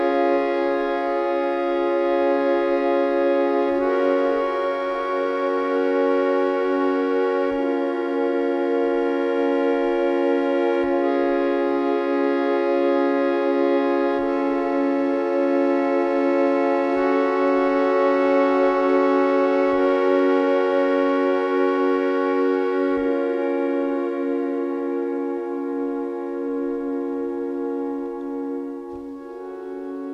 paysages ambient
les éléments percussifs et électroniques de son travail
créer son art sur scène